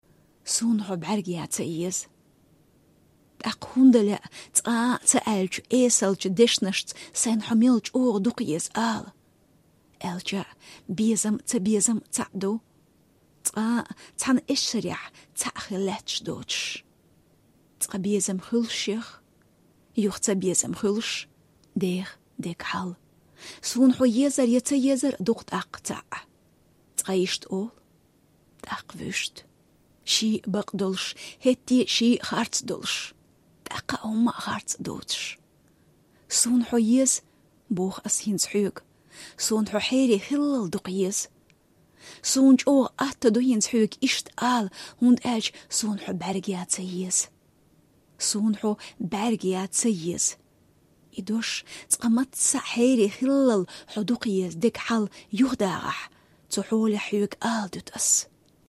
Russia/Chechnya -- Chechen language day 2014, Grozny, 25 April 2014.